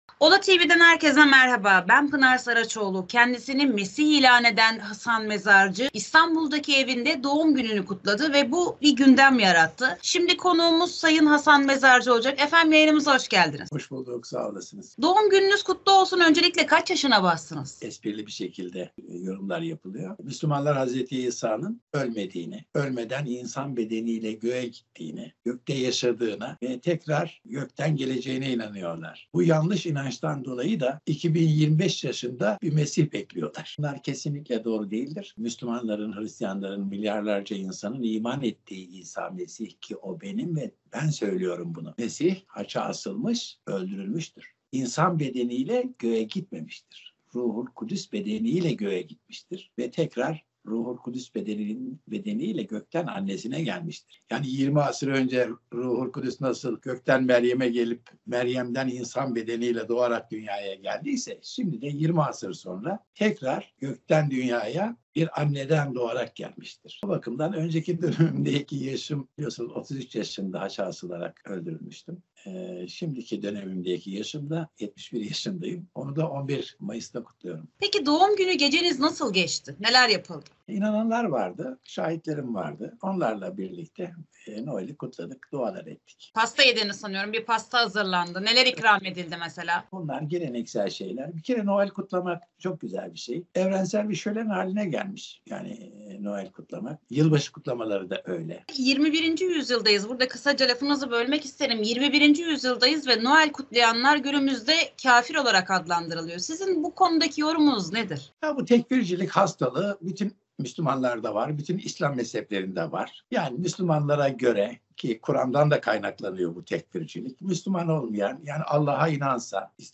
Allah ile ne sıklıkla konuşuyor? Pelerinin sırrı ne? Röportaj